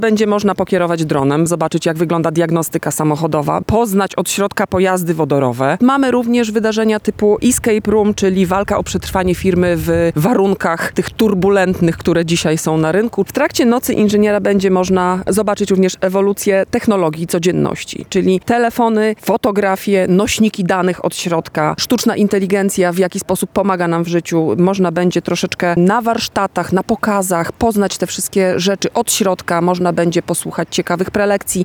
w rozmowie z Radiem Lublin